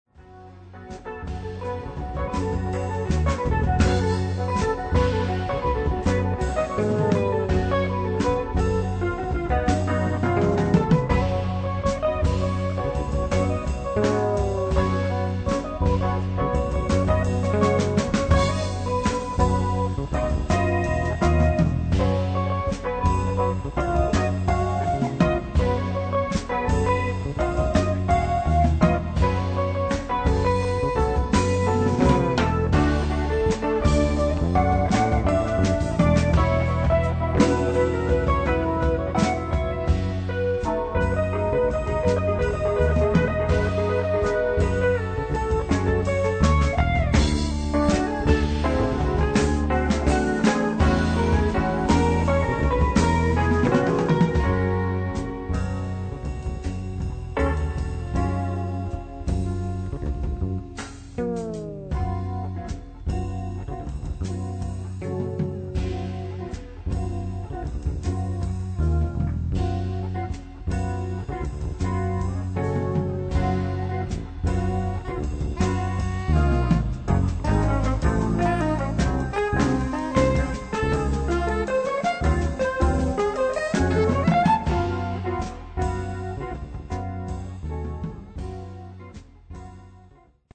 in eight funk and latin originals.
Guitar
Fretless Electric Bass
Drums
Soprano Saxophone